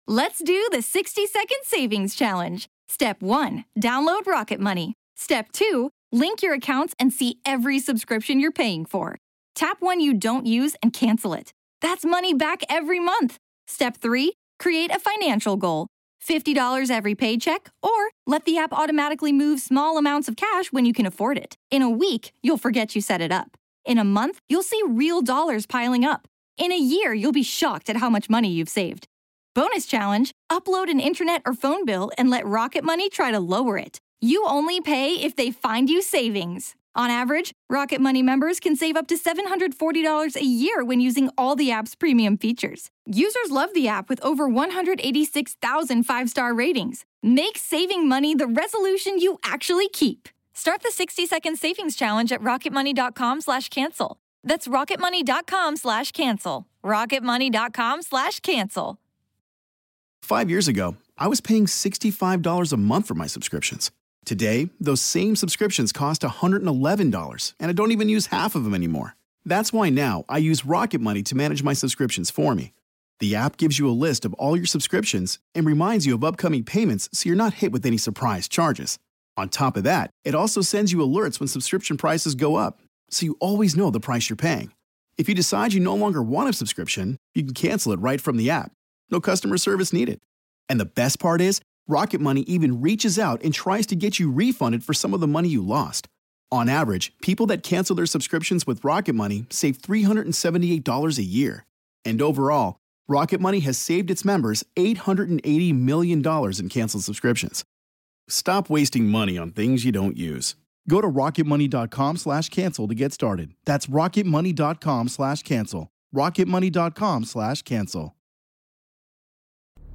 In tonight’s episode you will learn about a house in New Mexico that had a smelly haunting… Introduction voice over
Closing song